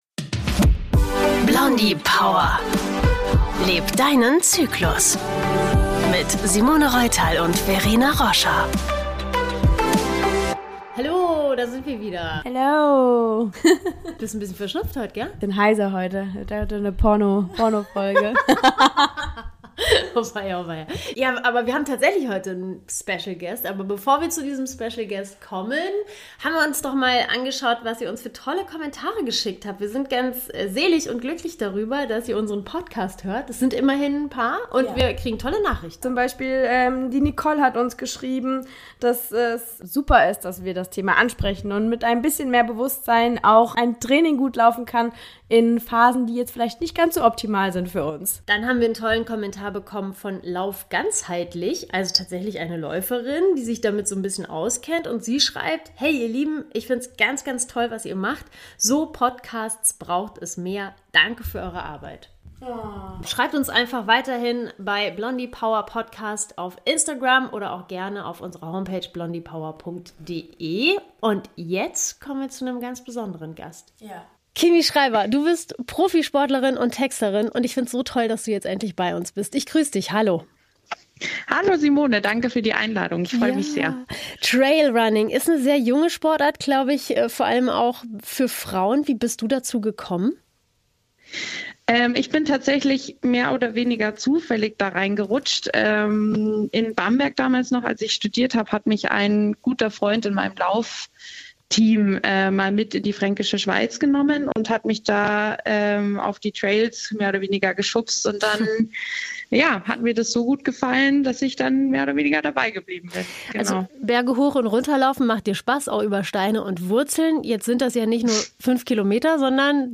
#6 Interview